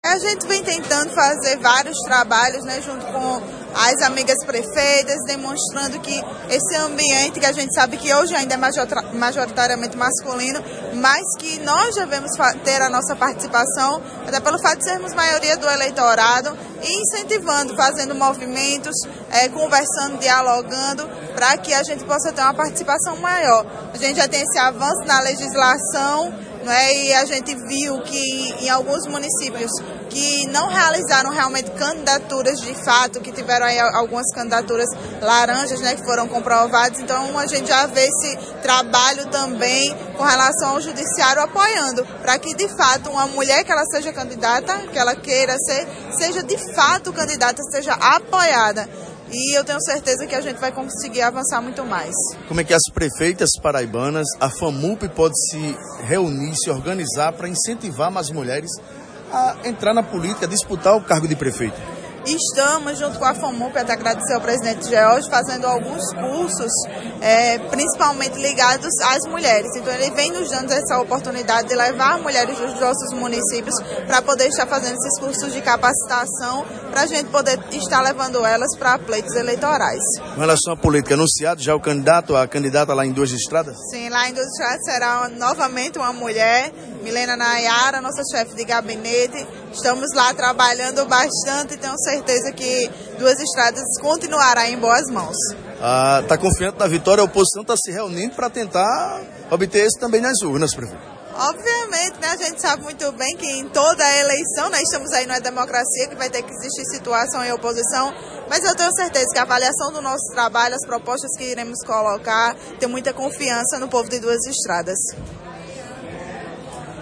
Prefeita Joyce Renally – entrevista ao PBVale.
Confira a seguir, entrevista concedida ao Portal PBVale: